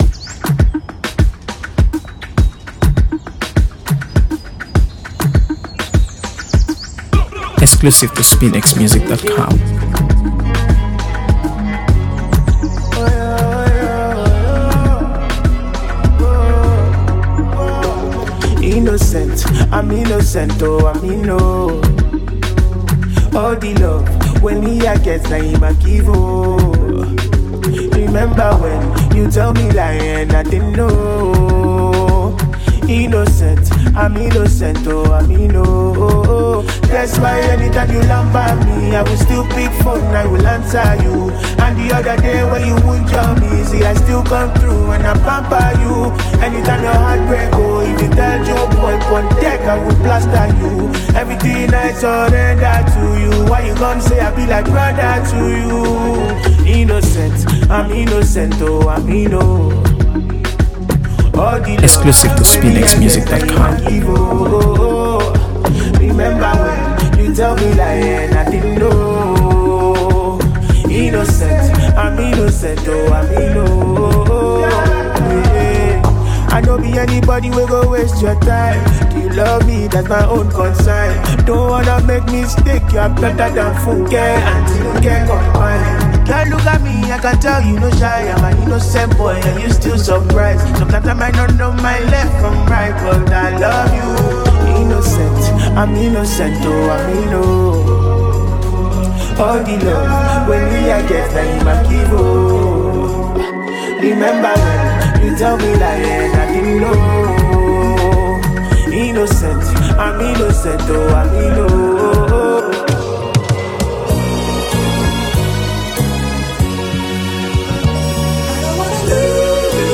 AfroBeats | AfroBeats songs
warm and layered soundscape
one of the most soulful voices in Afropop today.